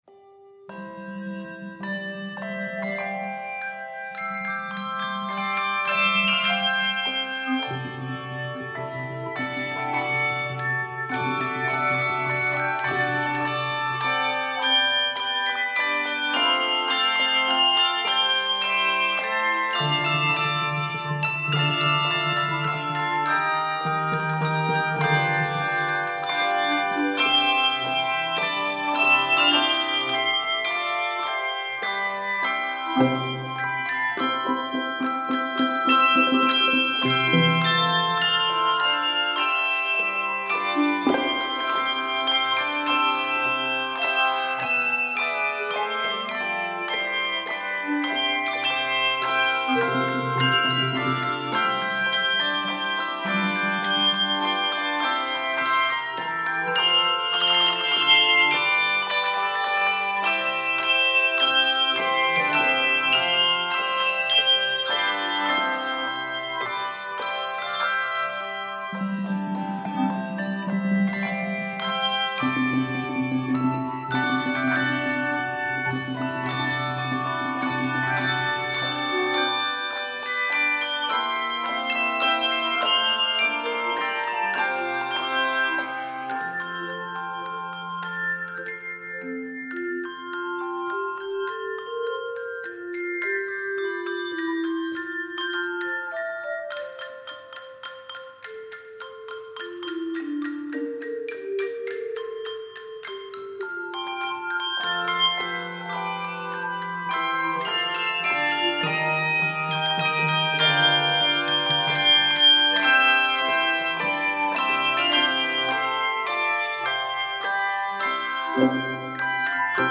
majestic and regal arrangement